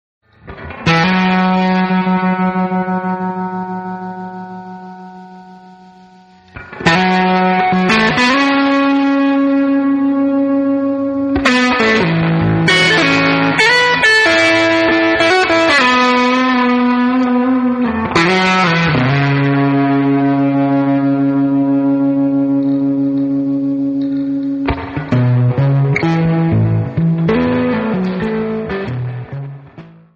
Blues
Rock